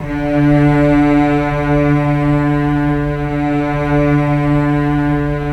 Index of /90_sSampleCDs/Roland L-CD702/VOL-1/STR_Symphonic/STR_Symph. Slow